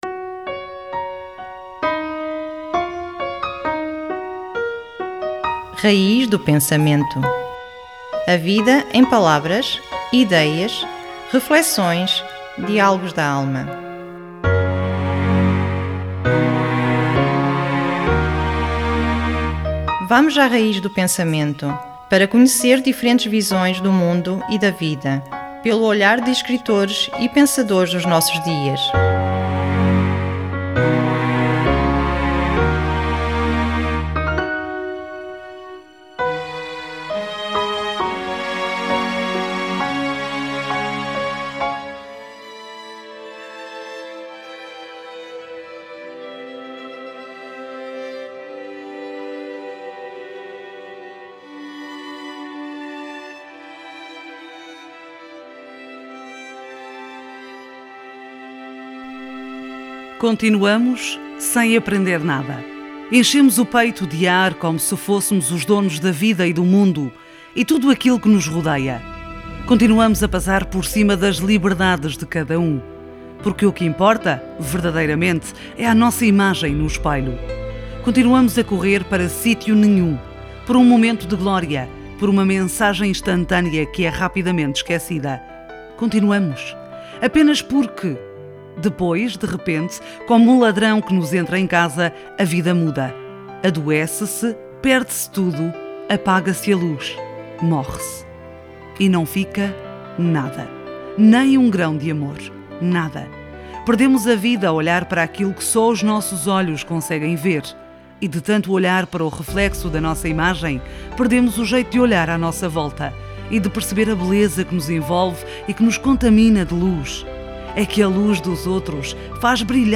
Um texto da escritora madeirense Graça Alves trazido à antena da rádio